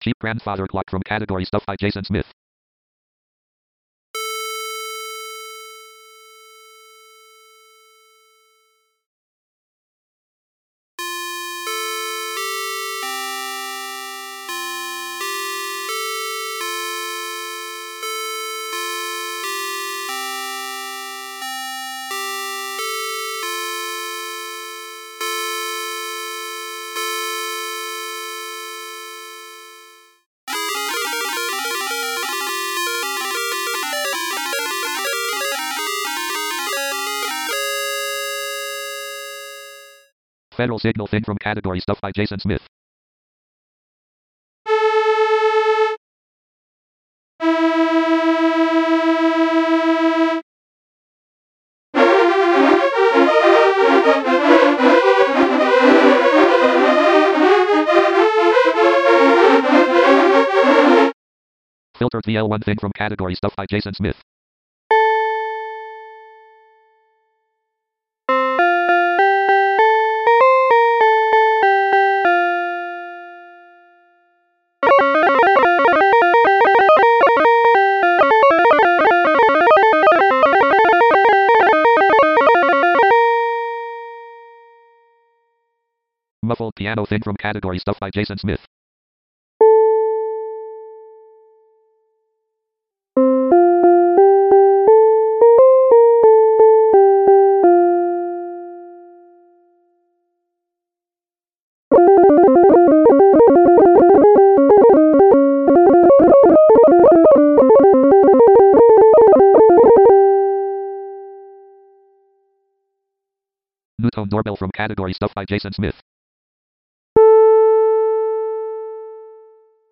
Here I am, sampling some Surge XT patches I've made in the past approximately eleven days. For each one, Eloquence announces the name, I play A above middle C (A440), play a little sample tune, then bang around on the keyboard. I'm using the on-screen keyboard since I don't have a real music keyboard hooked up.
Cheap Grandfather Clock
NewTone Doorbell